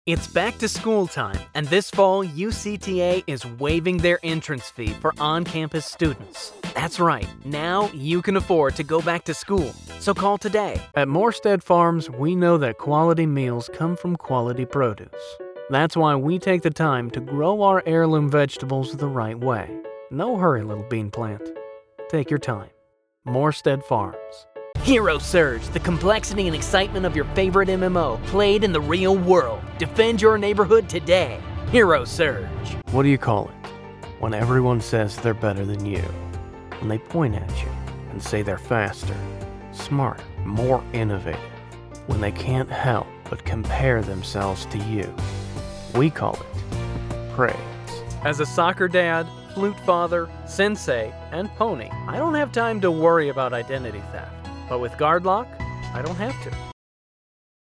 My voice is warm, trustworthy natural, sincere, authentic, versatile and can also be described as authoritative.